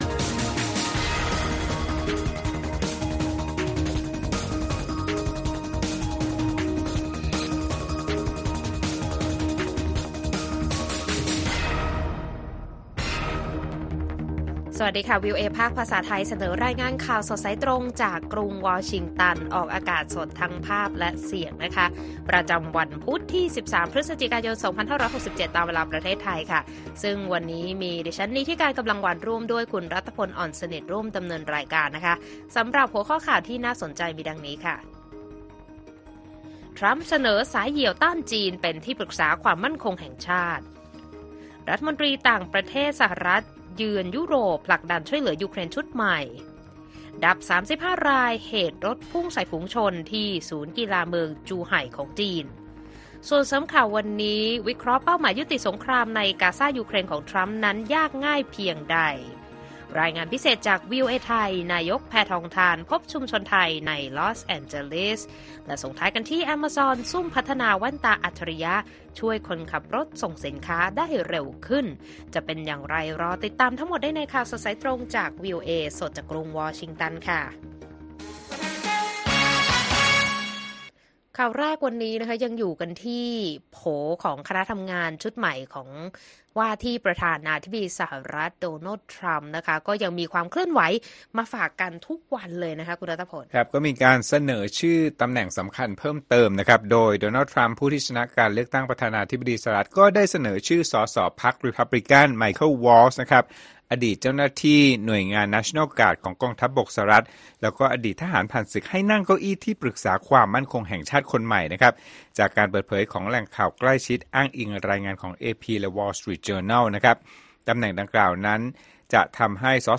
ข่าวสดสายตรงจากวีโอเอไทย พุธ ที่ 13 พ.ย. 67